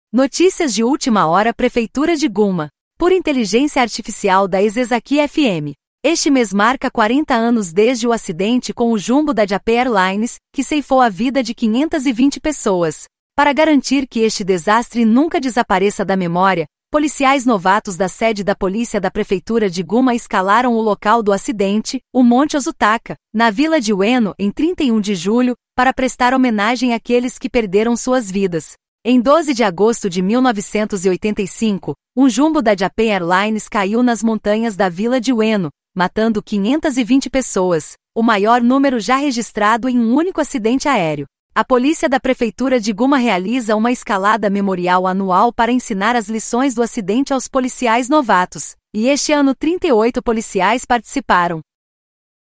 Notícias de Última Hora "Prefeitura de Gunma".Por AI da "Isesaki FM".Este mês marca 40 anos desde o acidente com o jumbo da Japan Airlines, que ceifou a vida de 520 pessoas.
Audio Channels: 1 (mono)